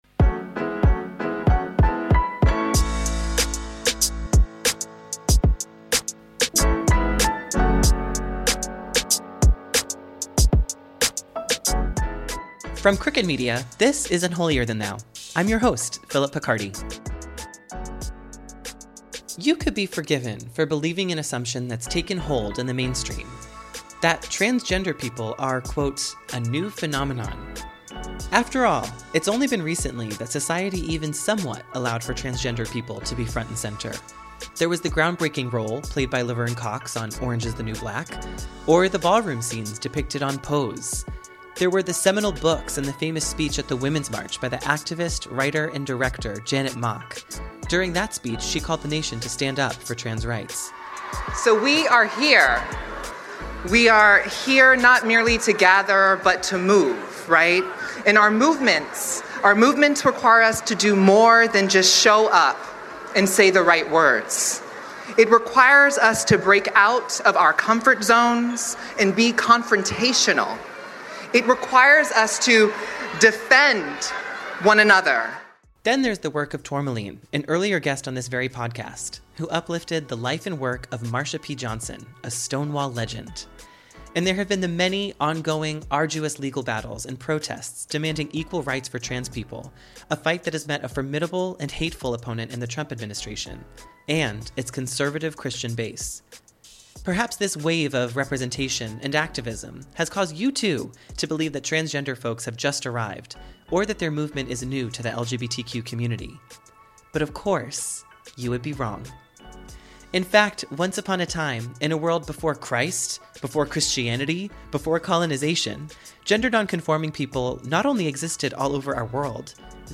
For many leaders of the movement, this is irony at its finest, because transgender people were once considered sacred in many pre-colonized societies. This week, we talk to advocate and model Geena Rocero about the gender non-conforming spiritual history of the Philippines to illustrate how trans people were once accepted and revered.